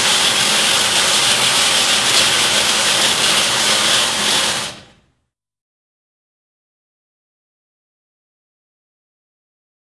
Звук «болгарки»